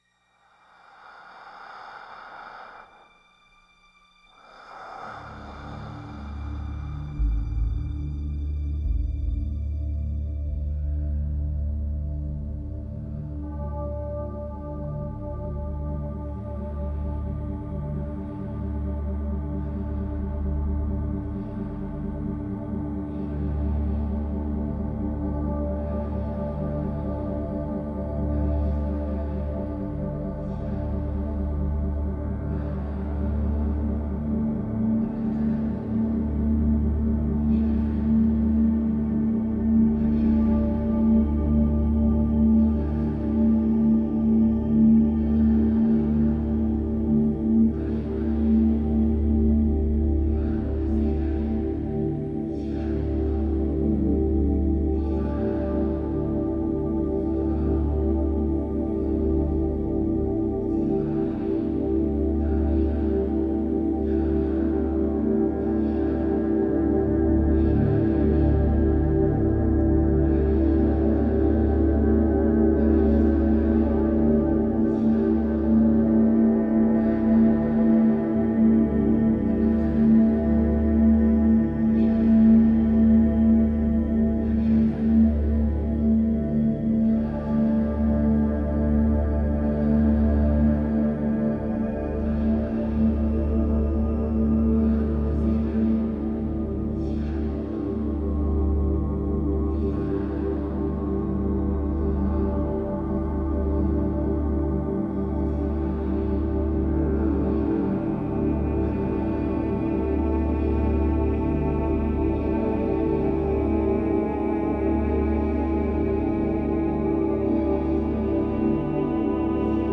Медитативная музыка Нью эйдж New age